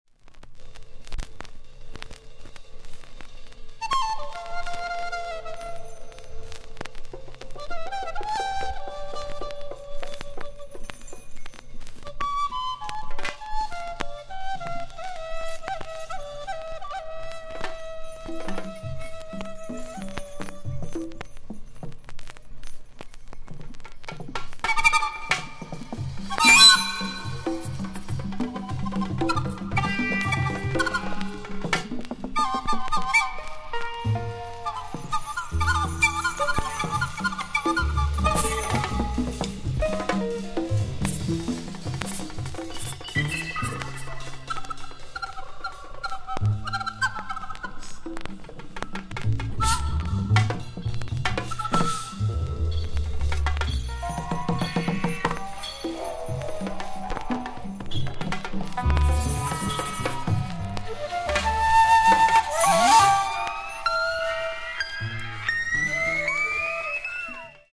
(注) 原盤の状態によりノイズが入っています。